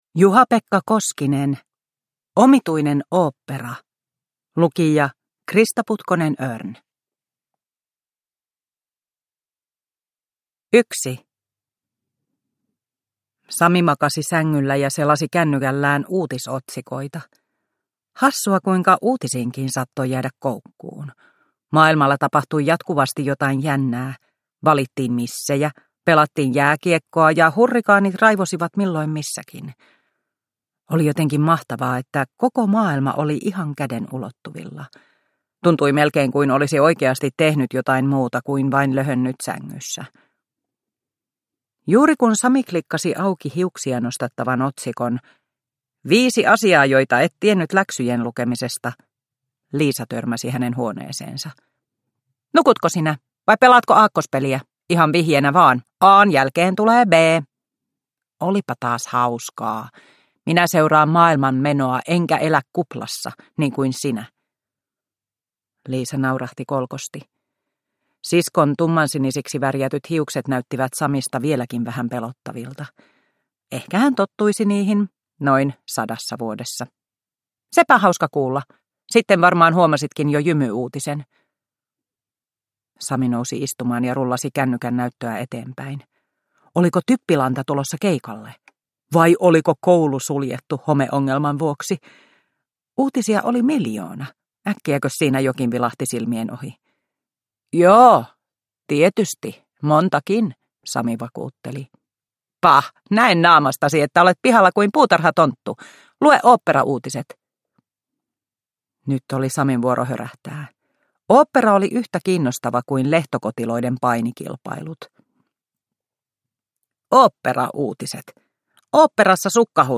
Omituinen ooppera – Haavekaupunki 6 – Ljudbok